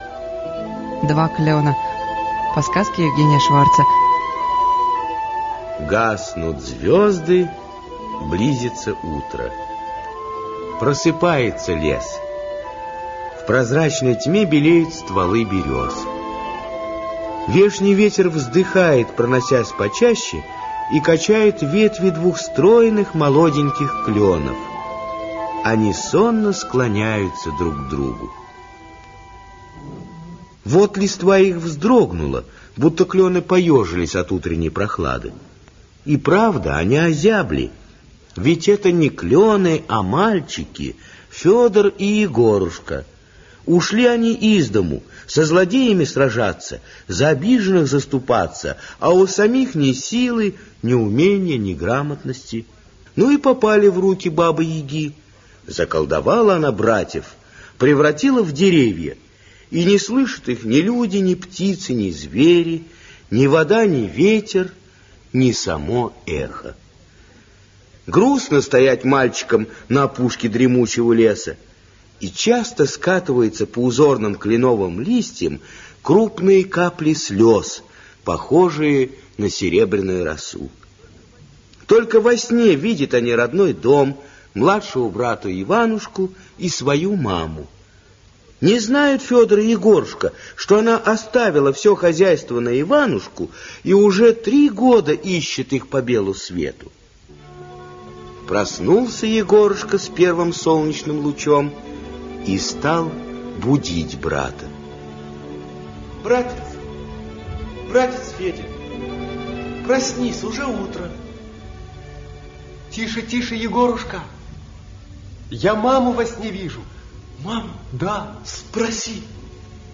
Два клена - аудиосказка Шварца Е.Л. Сказка о том, как Баба-Яга заколдовала у Василисы двух сыновей и превратила их в клены.